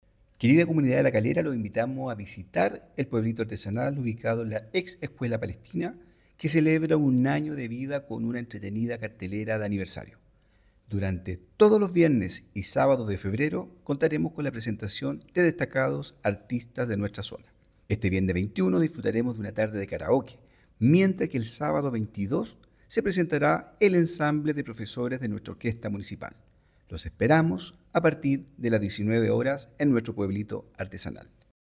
El alcalde Johnny Piraíno expresó su entusiasmo y extendió una invitación a la comunidad: